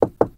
Huawei Bildirim Sesleri
Knock
Knock.mp3